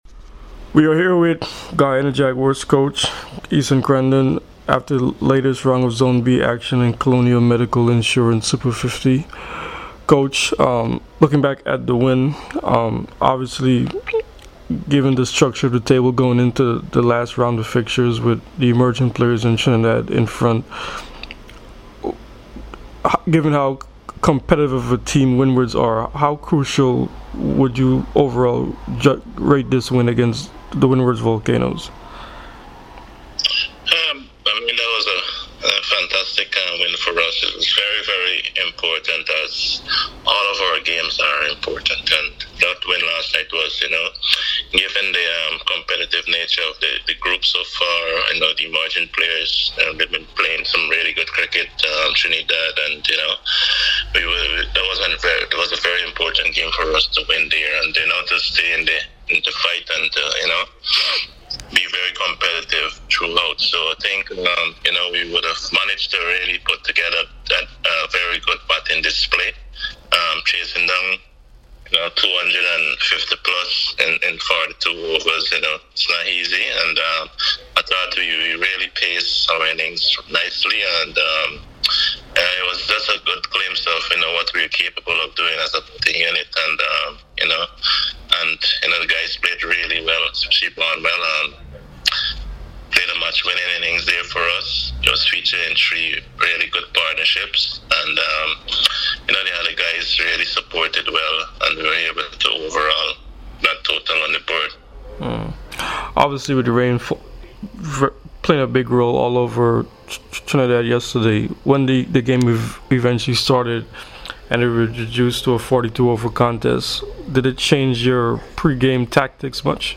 Esuan Crandon spoke to CWI Media after Zone “B” in the Colonial Medical Insurance Super50 Cup on Friday at Queen's Park Oval and Brian Lara Cricket Academy.